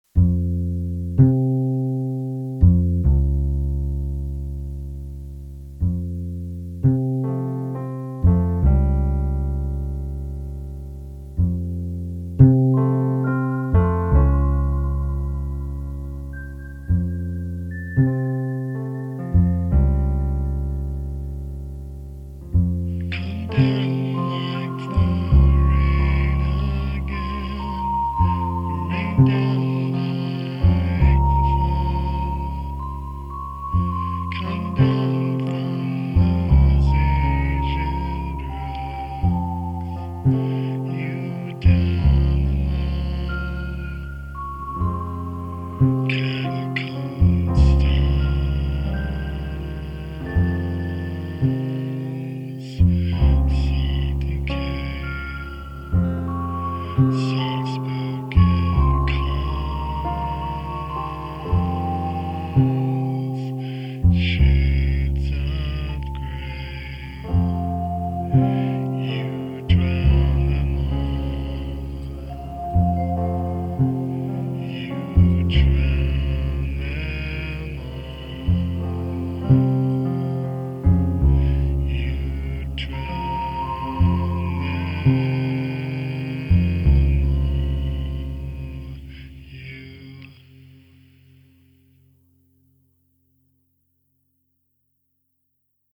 bass and a pair of keyboard tracks
atmospheric guitar